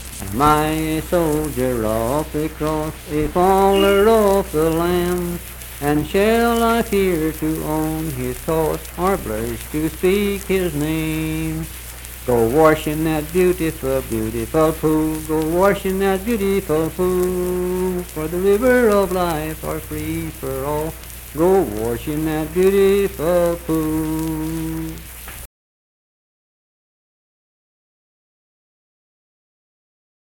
Unaccompanied vocal music performance
Hymns and Spiritual Music
Voice (sung)